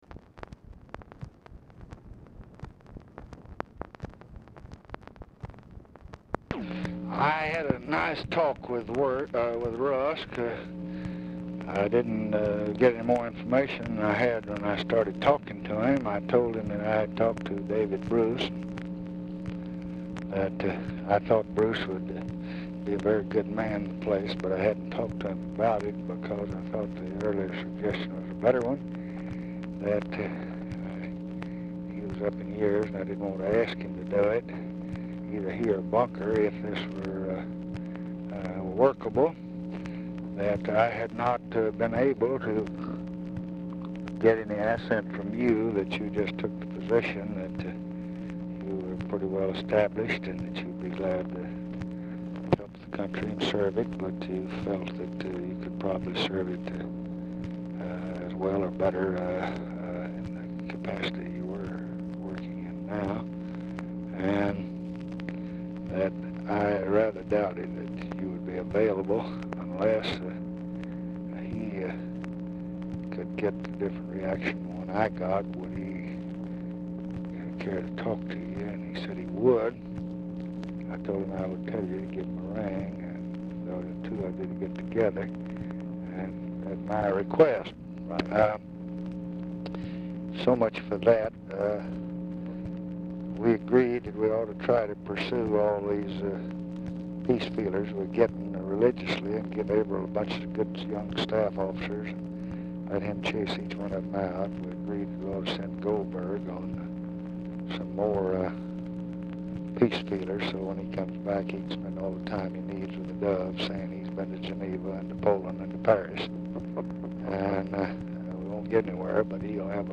Telephone conversation # 10526, sound recording, LBJ and CLARK CLIFFORD, 8/2/1966, 3:43PM | Discover LBJ
RECORDING STARTS AFTER CONVERSATION HAS BEGUN
Format Dictation belt
Location Of Speaker 1 Oval Office or unknown location